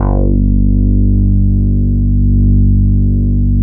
71 BASS   -R.wav